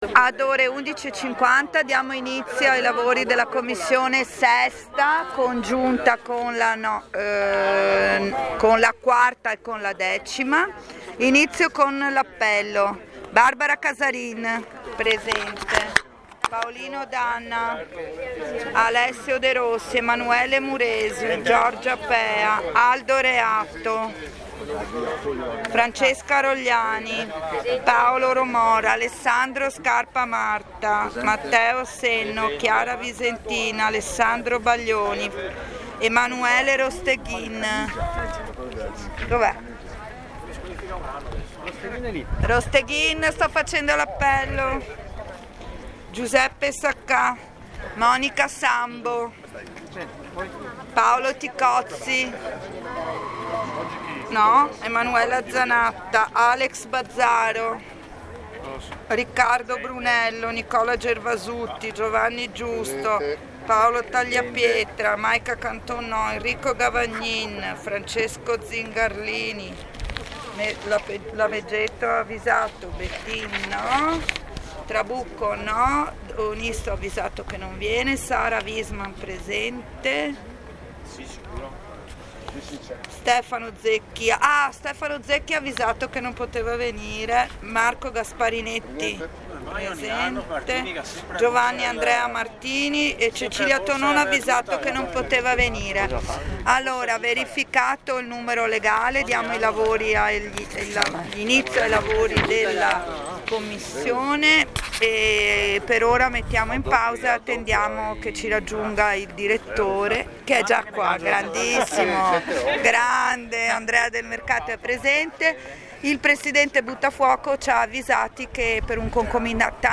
Incontro con il Presidente della Fondazione La Biennale di Venezia e sopralluogo agli spazi della 81. mostra internazionale dell'arte cinematografica di Venezia.
alle ore 11:50 la Presidente Giorgia Pea,verificato il numero legale apre i lavori di commissione.